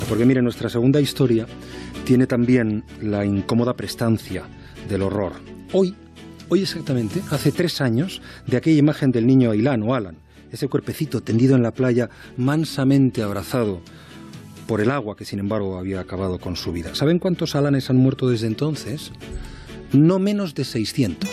Fragment de la primera edició del programa presentada per Juan Ramón Lucas
Informatiu